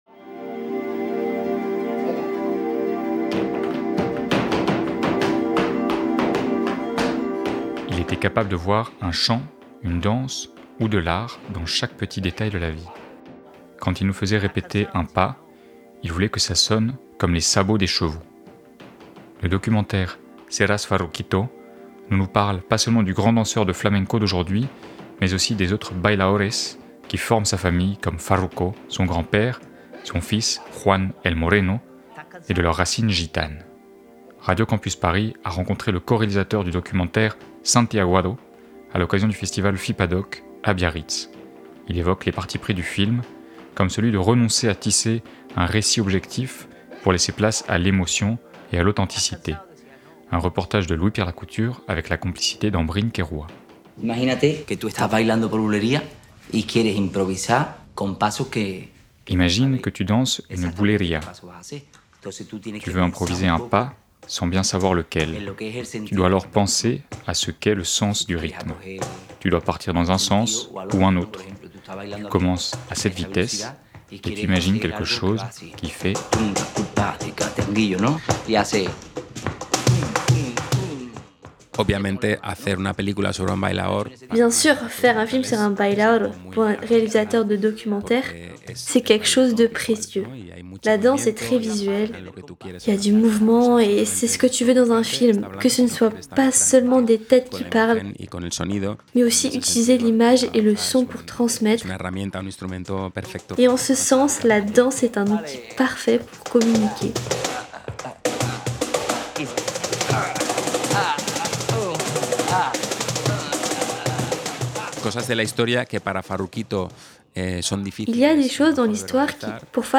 Magazine
En avril, La Matinale de 19h propose une série d'interviews enregistrées lors du FIPADOC 2026